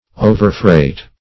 Search Result for " overfreight" : The Collaborative International Dictionary of English v.0.48: Overfreight \O`ver*freight"\, v. t. [imp.